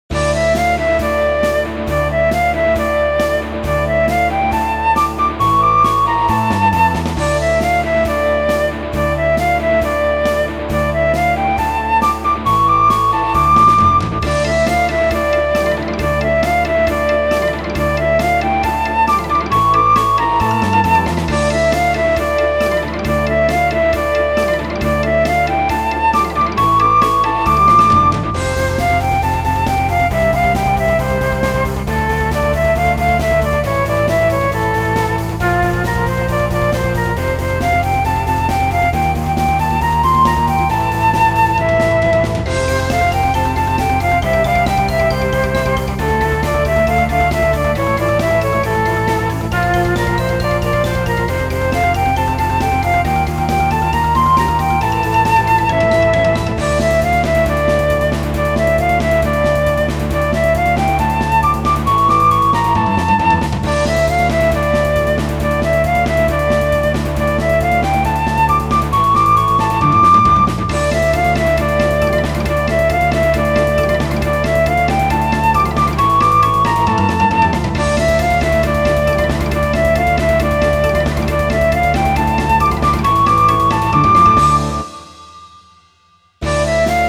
• 軽快なロックや慌てたものなどアップテンポな楽曲のフリー音源を公開しています。
ogg(L) ロック コミカル フルート